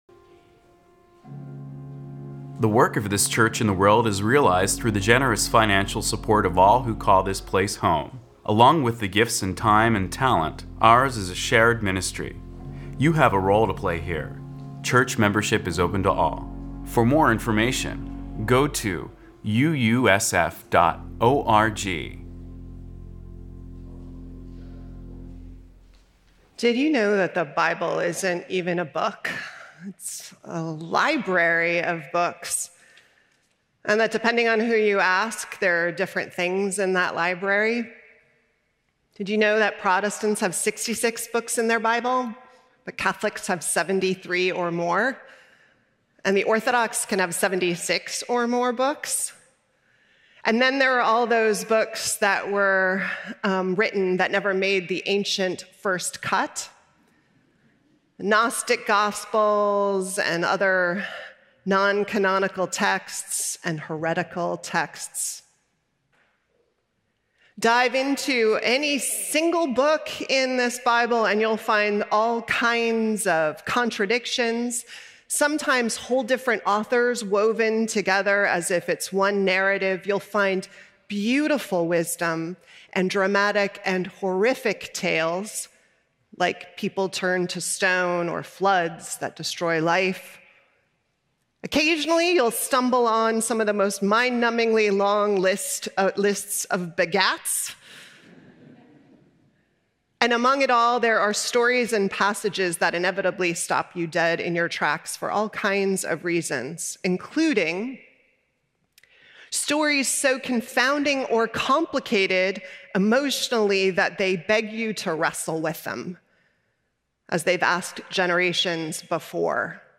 Guest Preacher
pianist
cellist